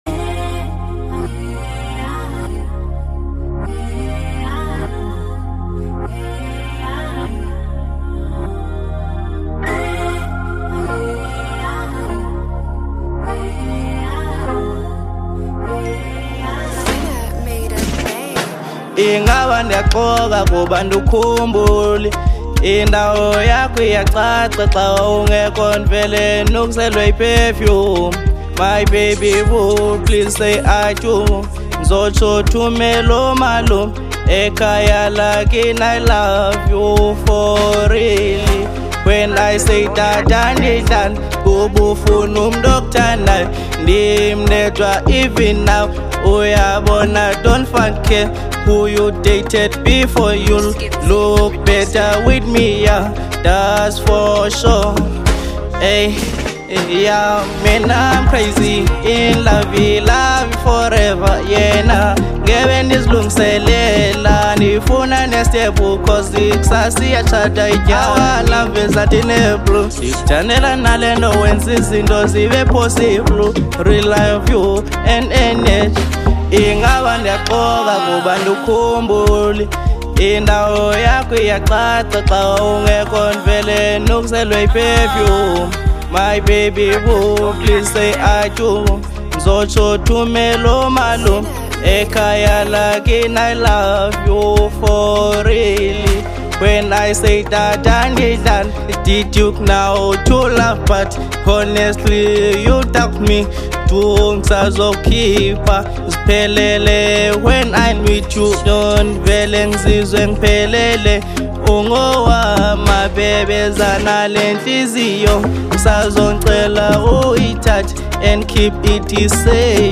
02:37 Genre : Hip Hop Size